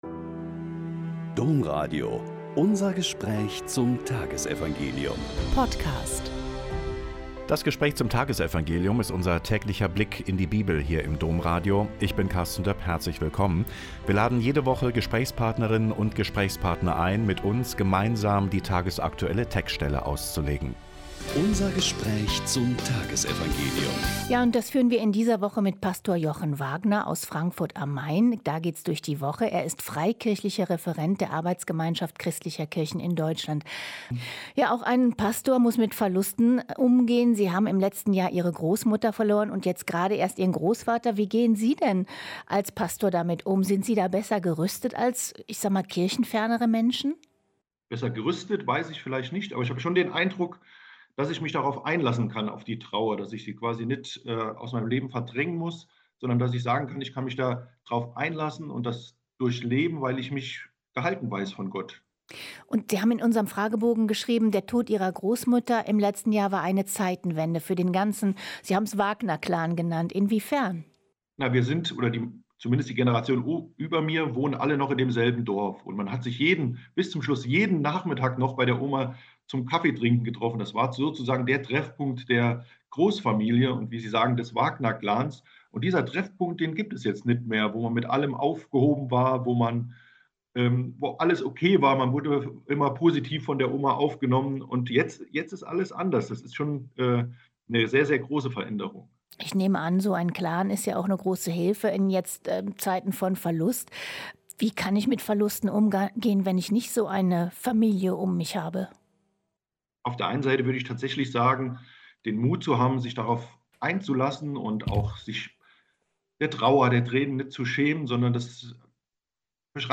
Mk 3,20-21 - Gespräch